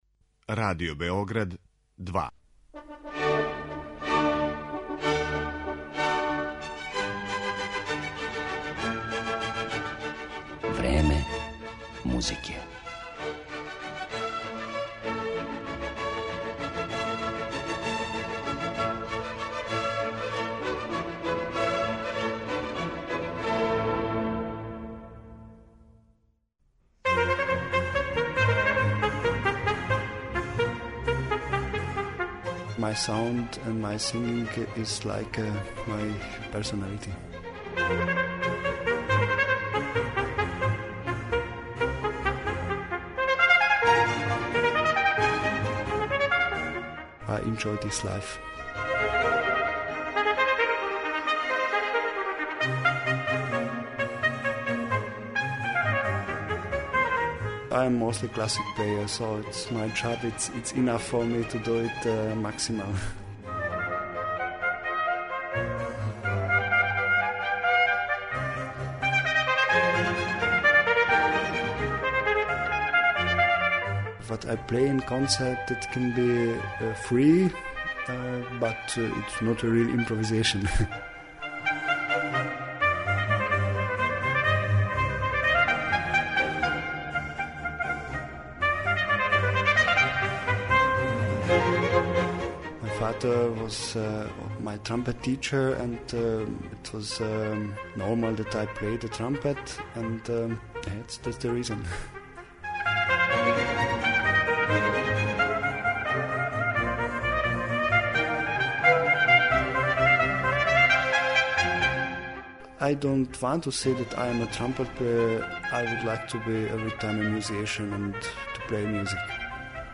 Једном од најбољих трубача данашњице, мађарском солисти Габору Болдоцком кога критичари описују као „Паганинија на труби" и наследника славног Мориса Андреа, посвећена је данашња емисија. Слушаћете га како изводи композиције Хенрија Персла, Карла Филипа Емануела Баха, Герга Фридриха Хендла, Волфганга Амадеуса Моцрта и Јохана Себастијана Баха.